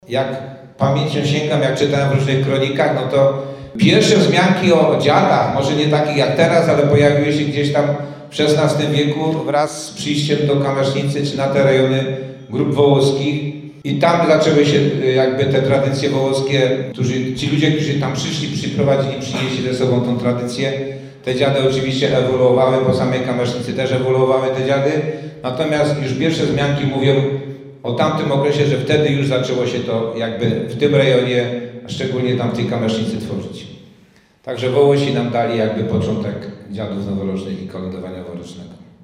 Słowa te padły podczas konferencji prasowej otwierającej 55. Przegląd Zespołów Kolędniczych i Obrzędowych "Żywieckie Gody".